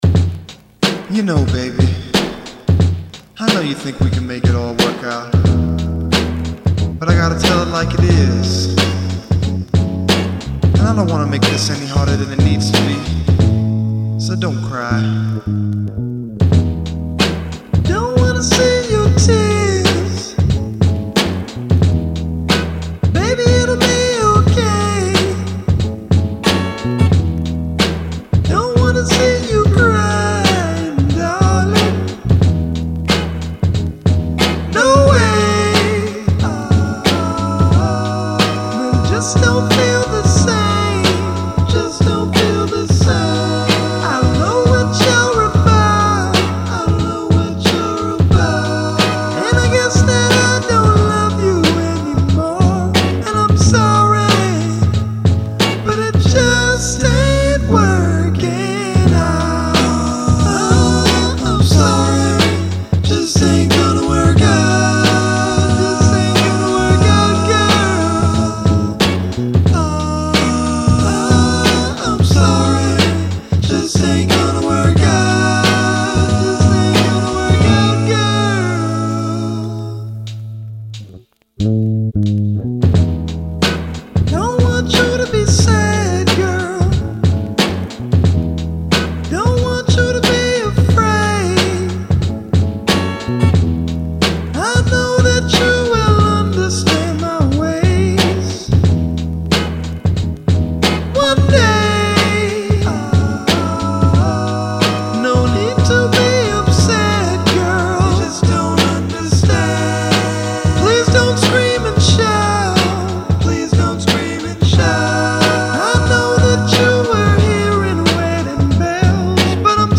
and this killer motown/doo-wop track.